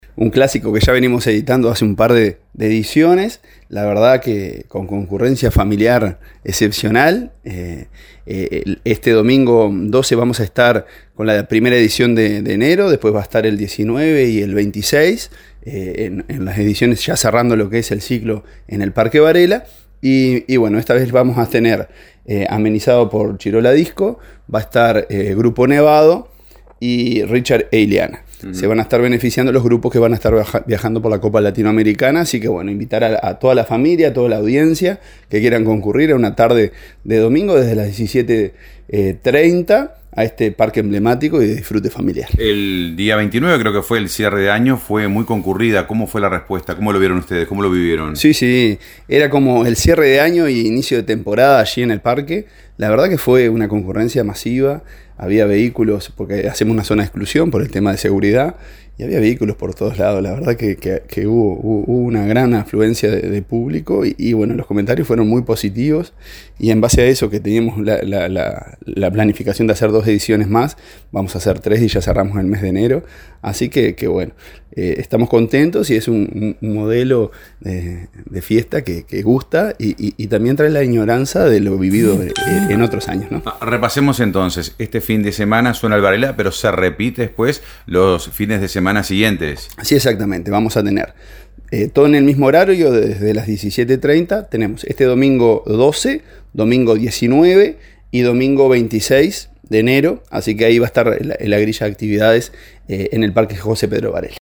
Al respecto dialogamos con el alcalde Marcelo Alonso.